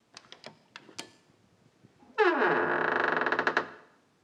SFX_Door_Open_02.wav